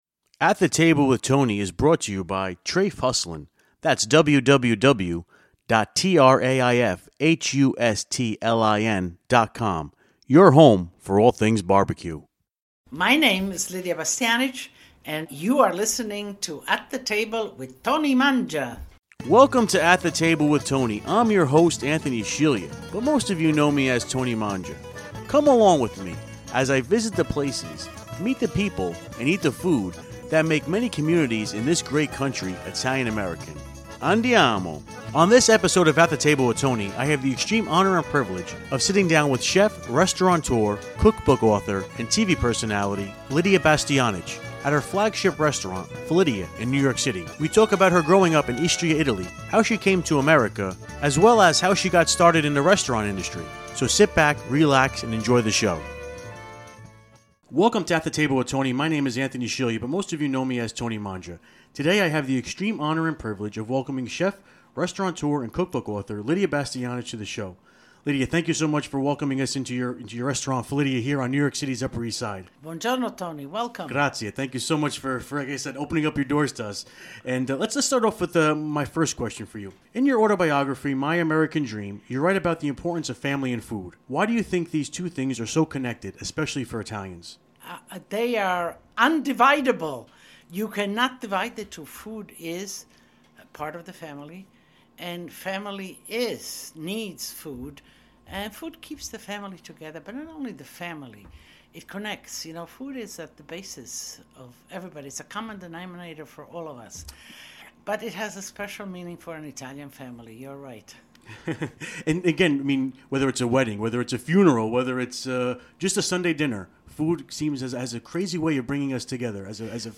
Interview with Lidia Bastianich